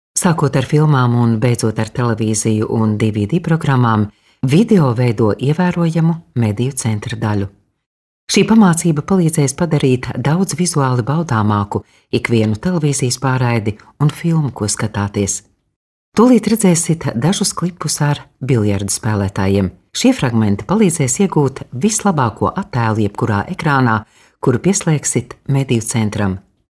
Kadın Ses